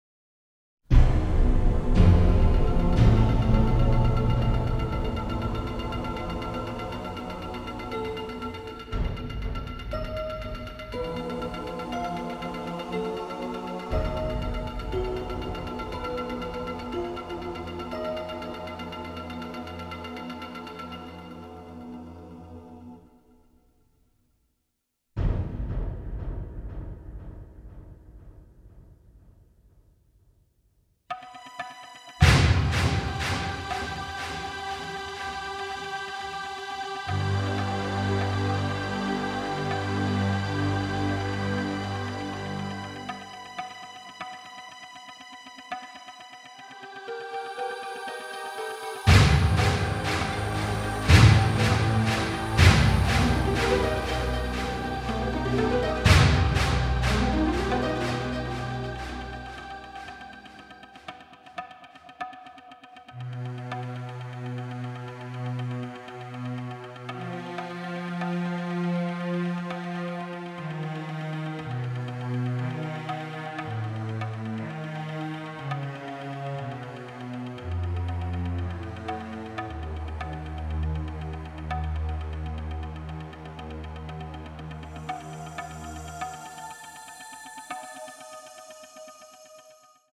contemporary electronics